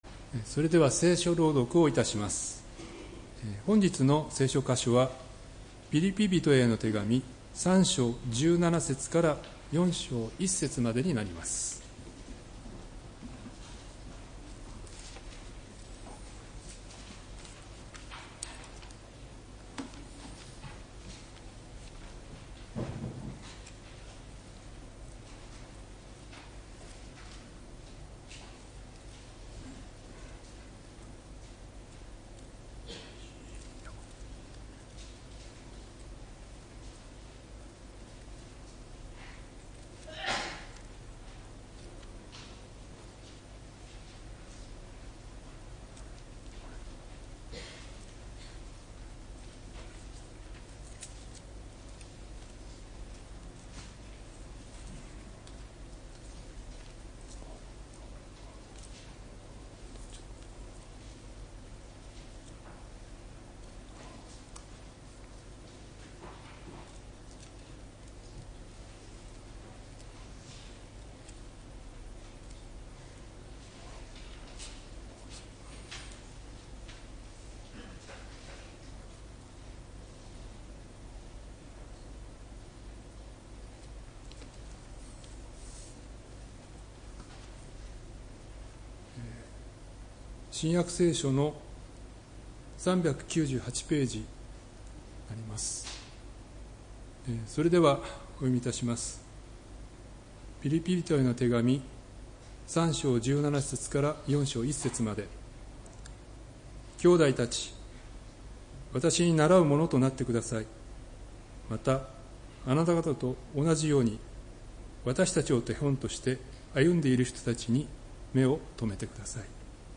礼拝メッセージ「国籍にふさわしく」（1月25日）